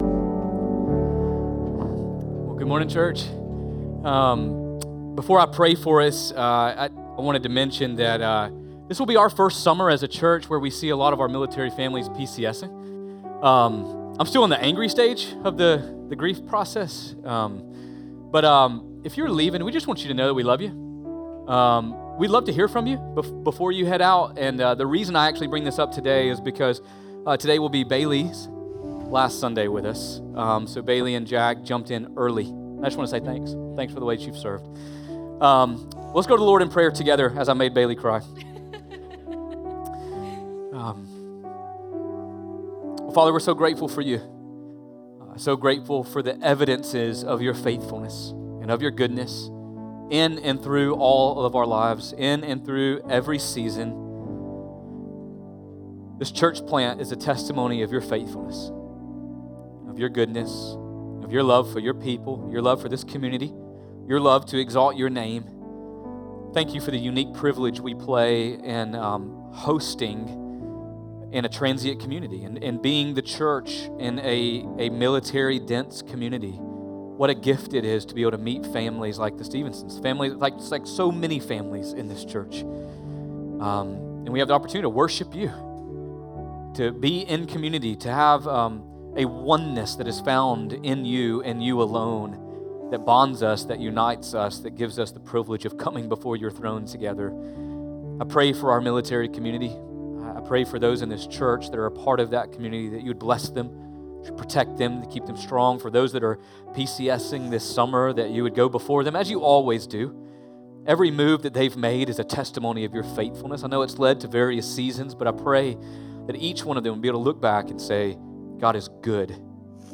Sermons | Community Bible Church of Richmond Hill